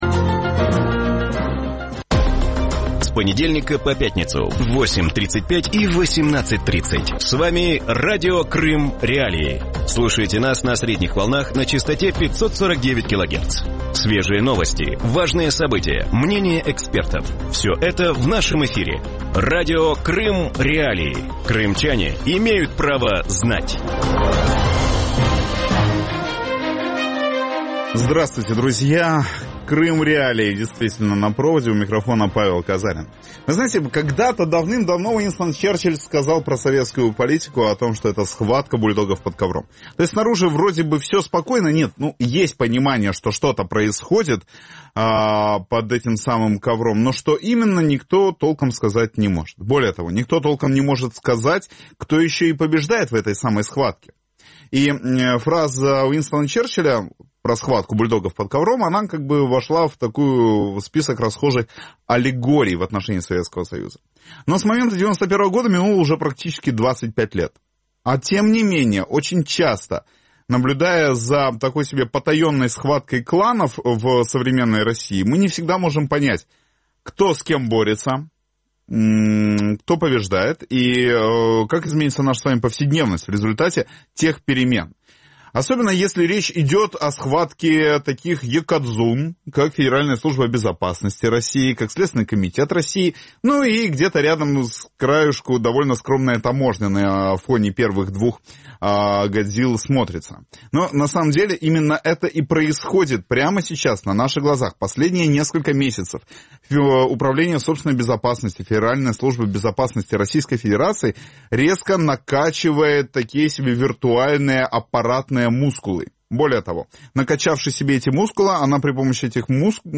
У вечірньому ефірі Радіо Крим.Реалії обговорюють обшуки у високопоставлених співробітників Слідчого комітету Росії і глави Федеральної митної служби. Що це – боротьба з корупцією у вищих ешелонах влади або внутрішньовідомча боротьба?